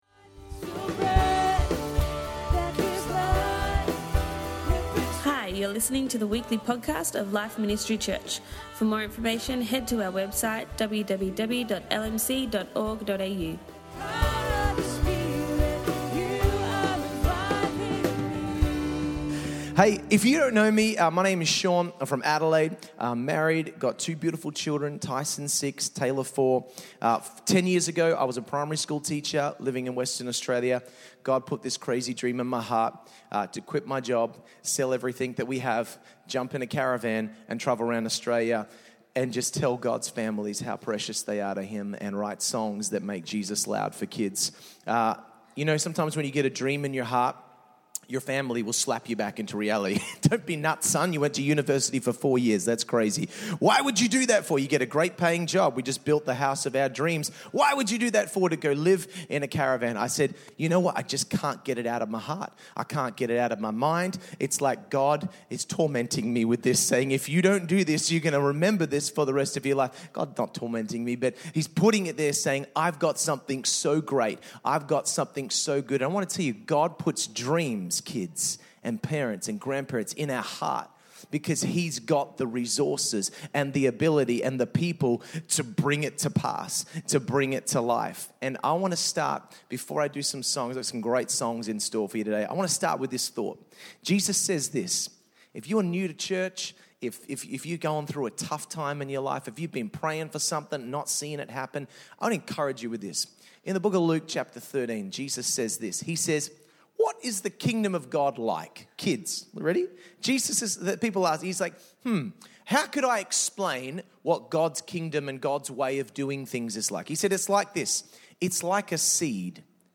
Family Service
We enjoyed singing and dancing to truth filled songs